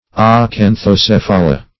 Acanthocephala \A*can`tho*ceph"a*la\, n. pl.